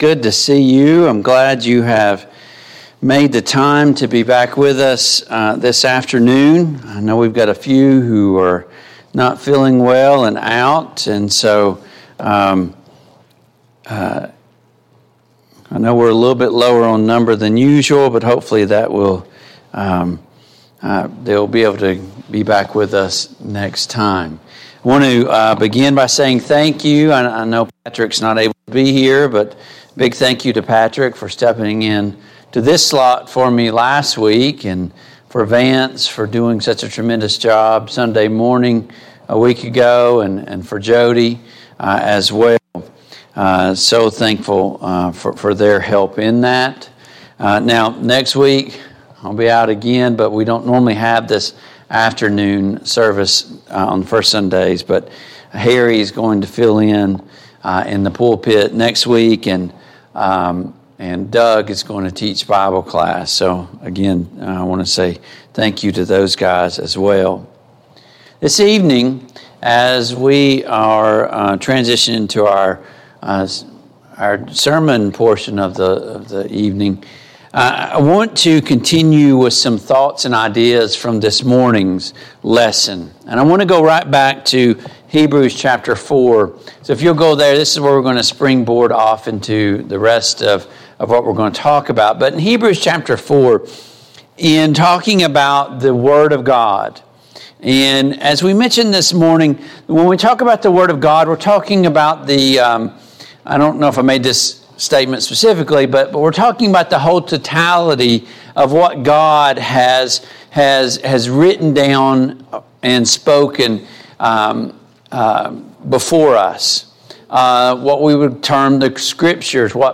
Passage: Hebrews 4:12-13 Service Type: PM Worship Download Files Notes Topics: Judgment Day , The Word of God , truth « 1.